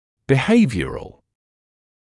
[bɪ’heɪvjərəl][би’хэйвйэрэл]поведенческий, бихевиористский